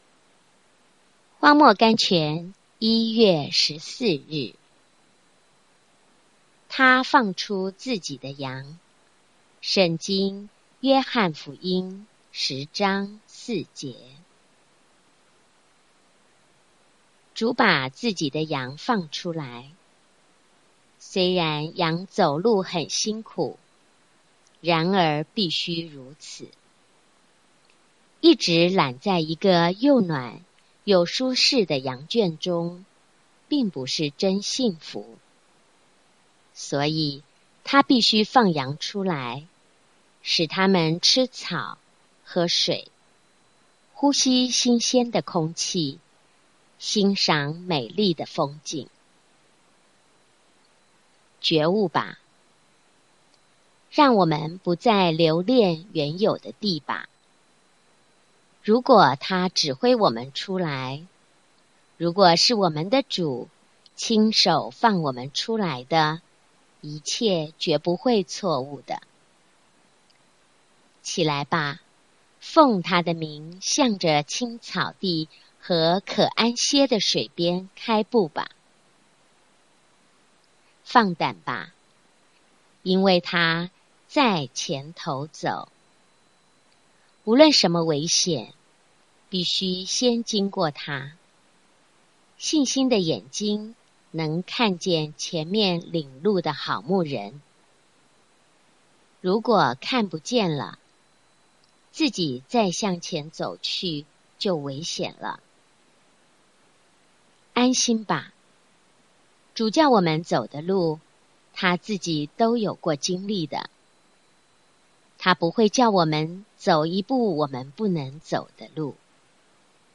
voice聆聽朗讀